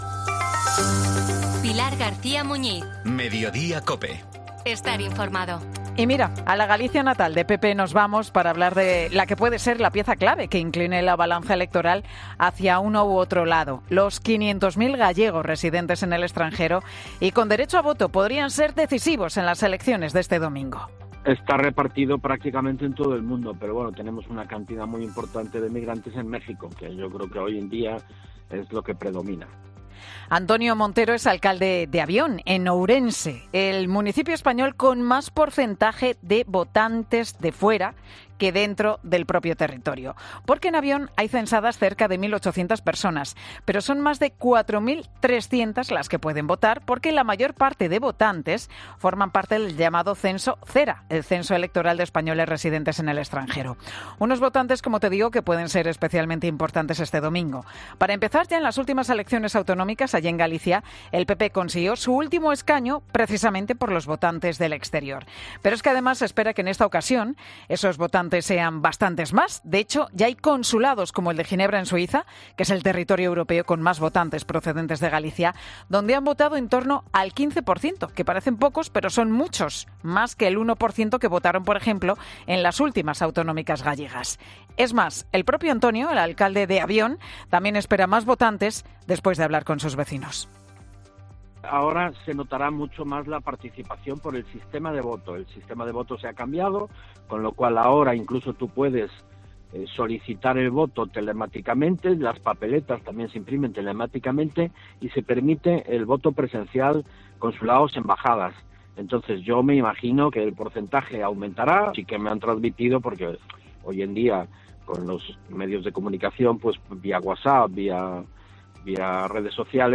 Antonio Montero, alcalde de la localidad orensana de Avión, en Mediodía COPE